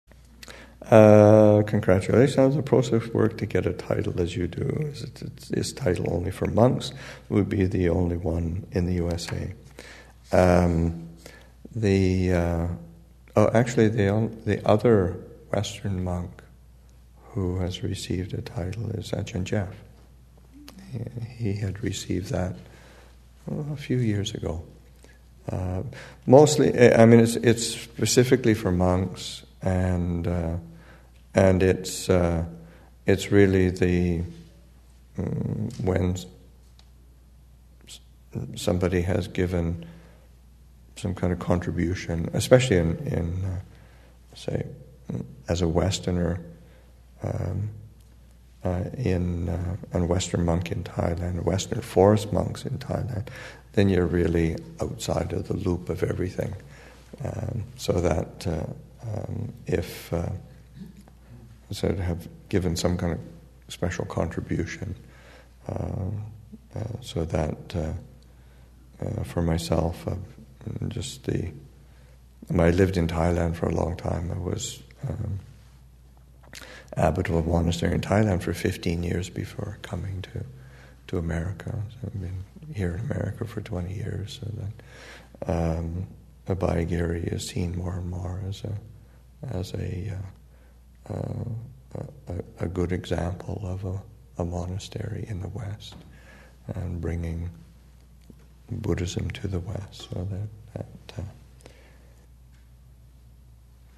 2015 Thanksgiving Monastic Retreat, Session 8 – Nov. 28, 2015